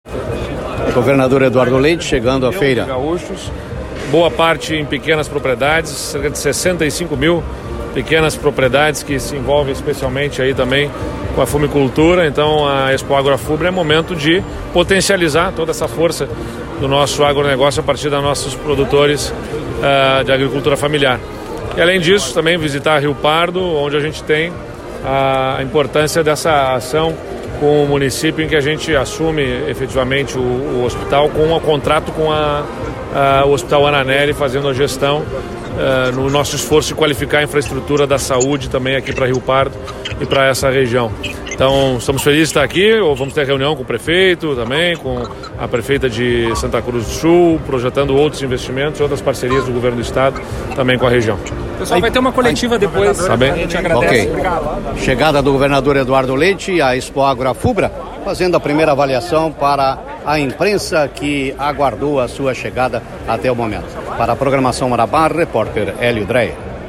Entrevista com o governador Eduardo Leite